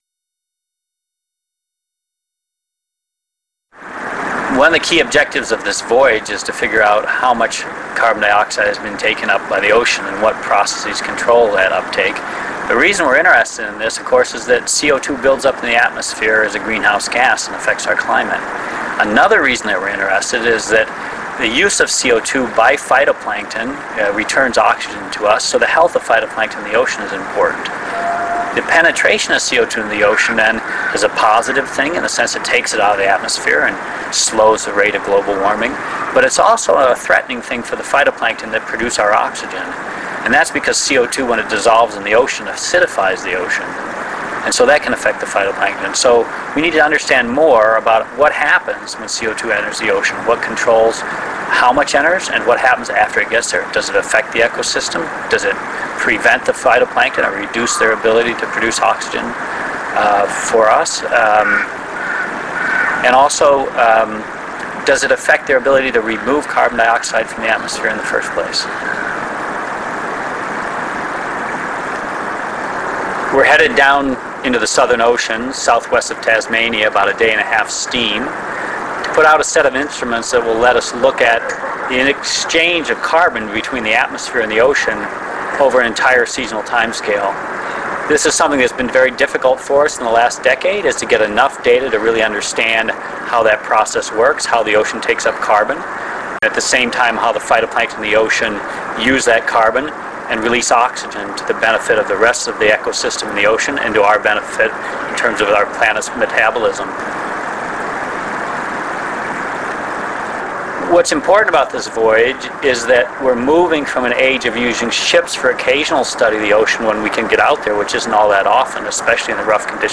Scientist Interviews